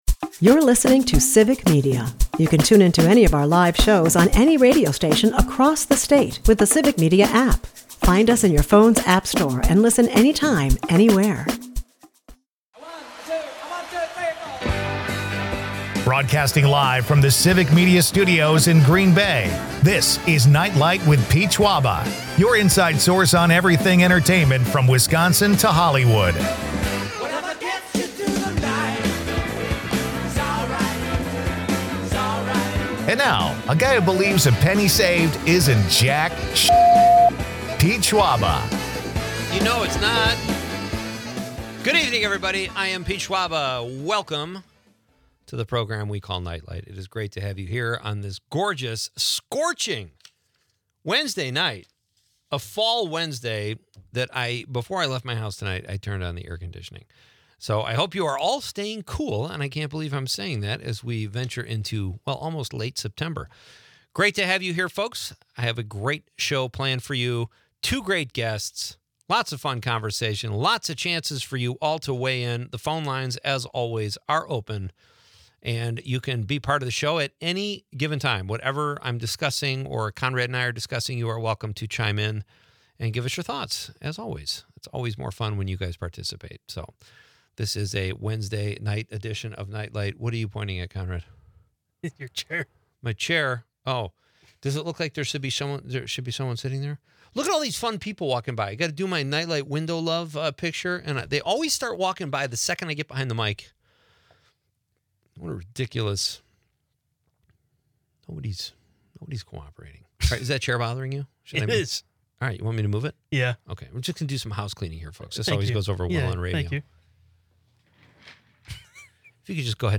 The show also features comedic banter about the improbability of ordering five sides on DoorDash, the phenomenon of Chevy Equinoxes, and a spirited debate on the best athlete-turned-actor, with mentions of Carl Weathers and Fred Dryer.